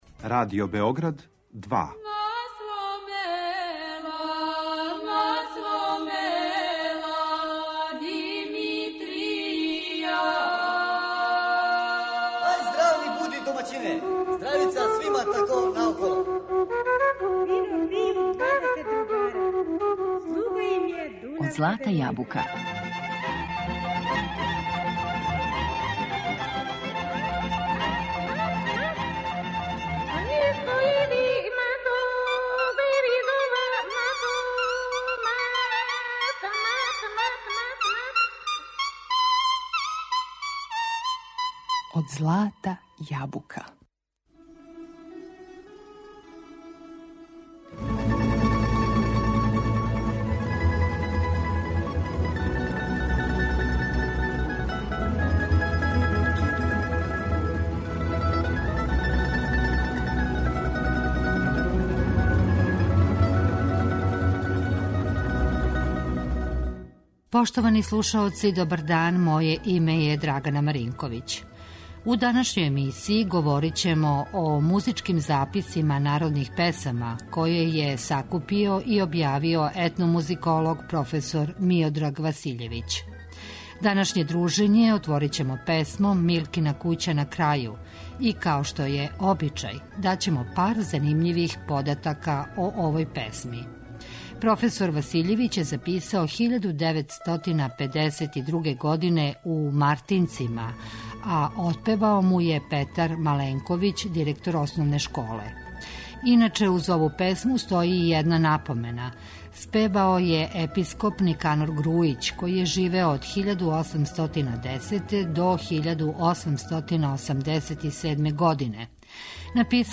Народне песме из Војводине